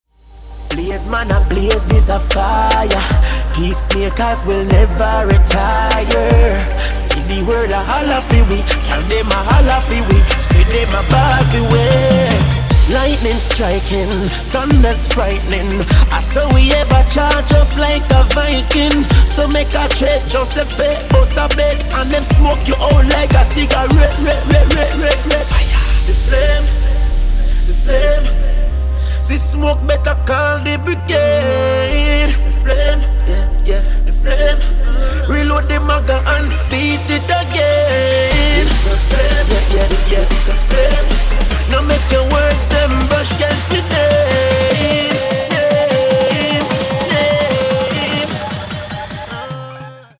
• Качество: 160, Stereo
мужской вокал
Хип-хоп